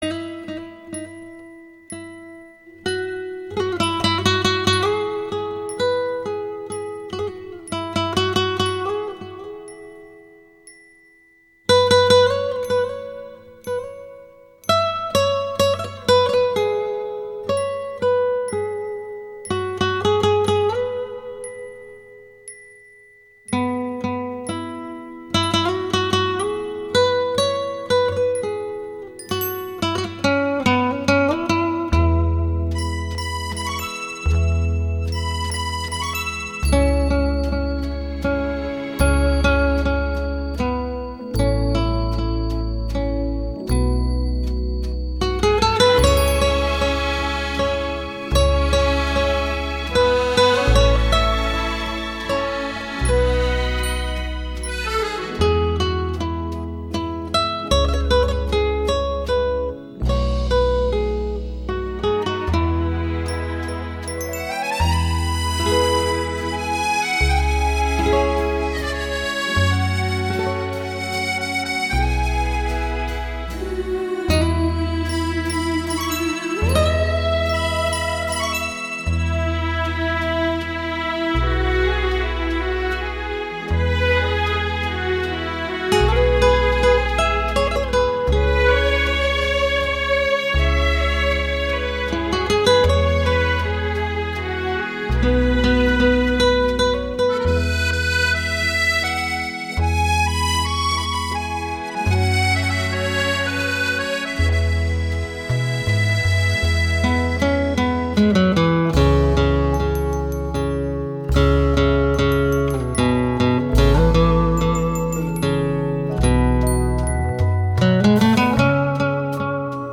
★ 演歌界無可取代的重量級吉他大師，靈巧指法與動人尾韻無間斷挑動您的耳朵！
★ 日本壓片，錄音清澈透明，吉他形體極富肉感，帶來最發燒最立體的演歌饗宴！